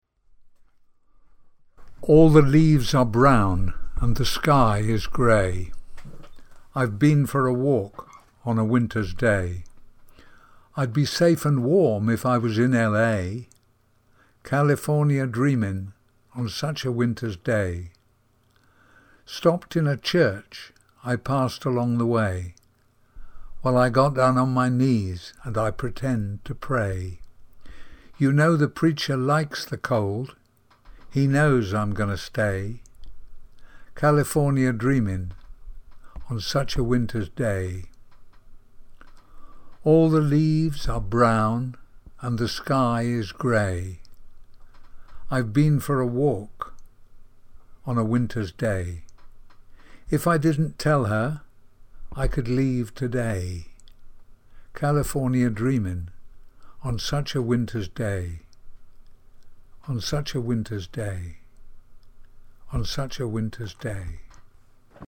Fichiers de prononciation
California Dreamin Pronunciation.mp3